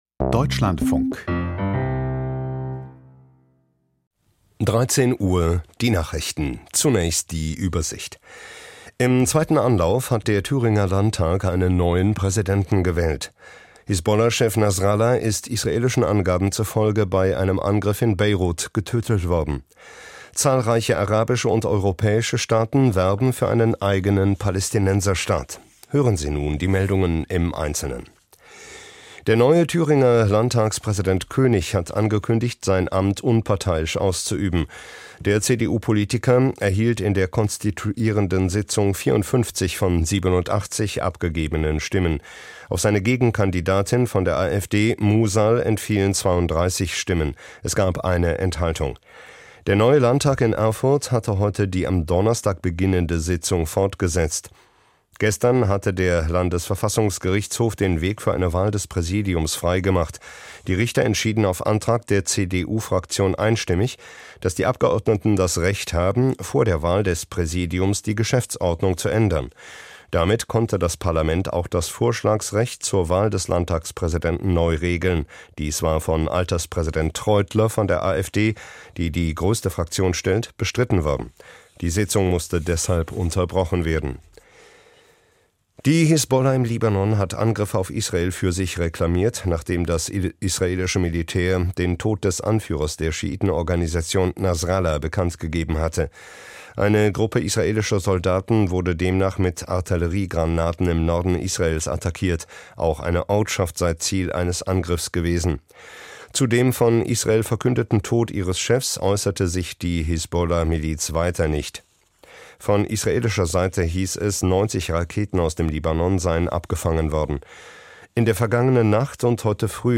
Kommentar zu Bündnis90/Die Grünen: Austausch von Personal allein genügt nicht - 28.09.2024